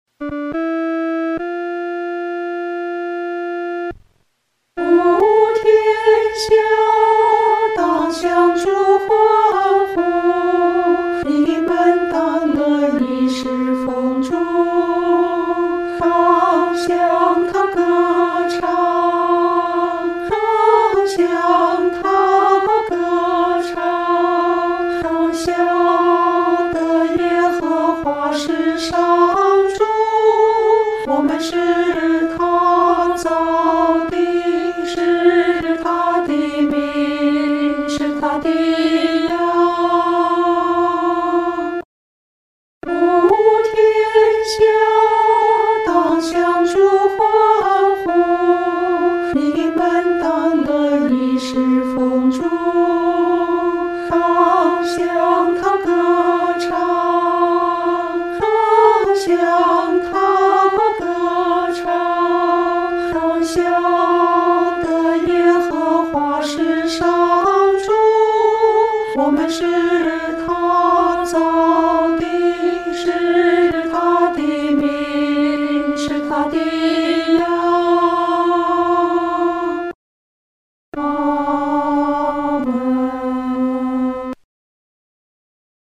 合唱
女低
本首圣诗由网上圣诗班 （南京）录制
此曲主要刻划梅花洁白、清新的形象，曲调活泼，节奏明快，故改编者选择用它为《诗篇》第100篇所用。